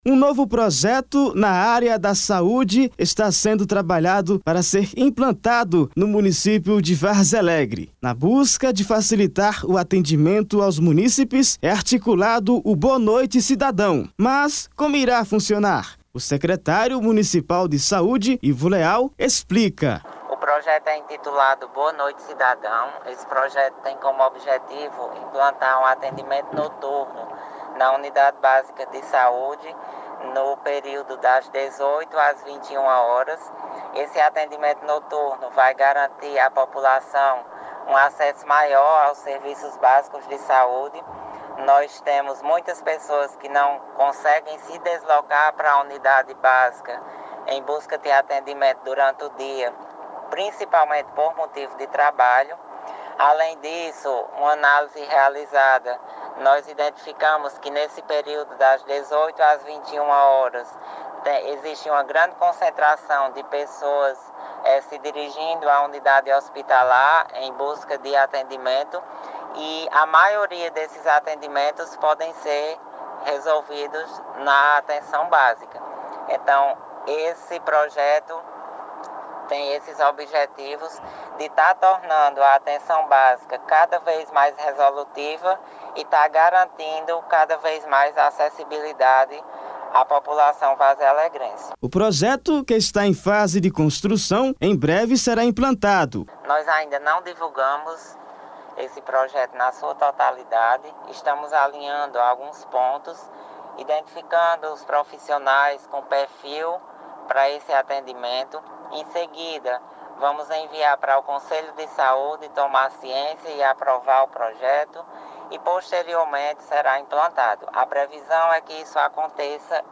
O secretário municipal de saúde, Ivo Leal, explica. Ele ainda disse que o projeto que está em fase de construção em breve será implantado e que o atendimento noturno facilitará também o trabalho dos profissionais da unidade hospitalar.